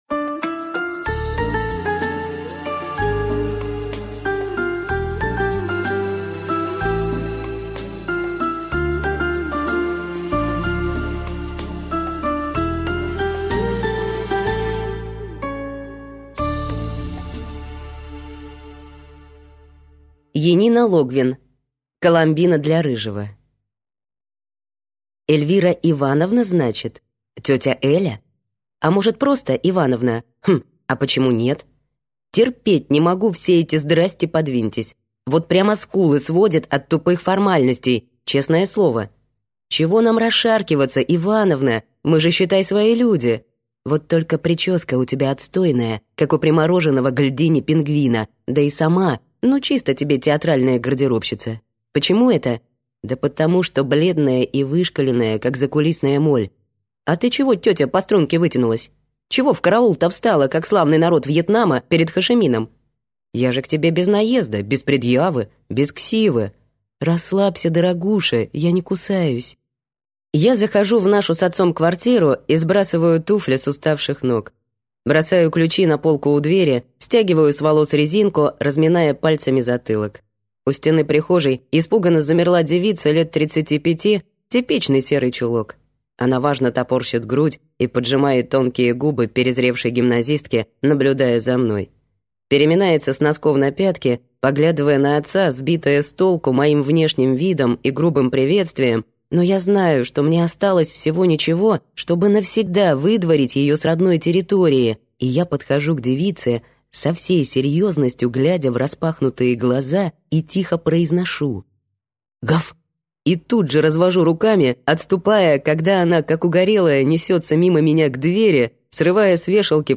Аудиокнига Коломбина для Рыжего | Библиотека аудиокниг